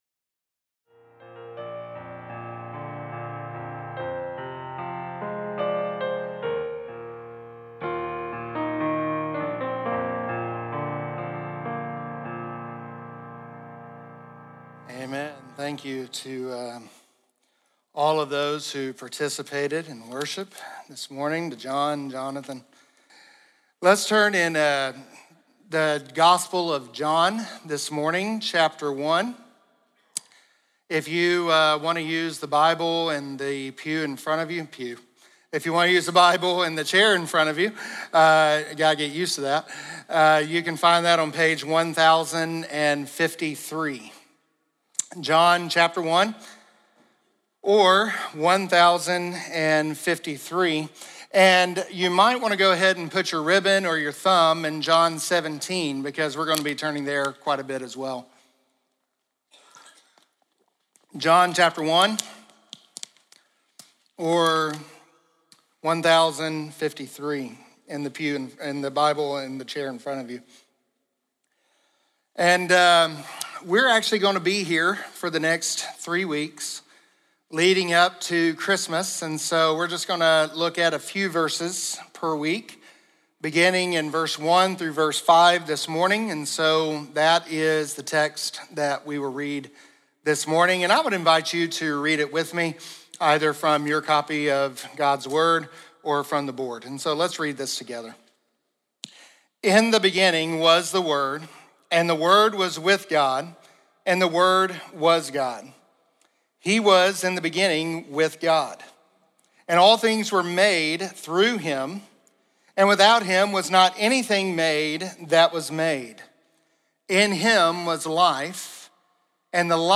Today we kicked off our series of Christmas sermons, looking at John 1:1-18 and John 17 and how we can delight in the mystery of God becoming man, stepping into the world He created, to ultimately become the sacrificial lamb providing redemption for our sins.